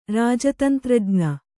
♪ rāja tantrajña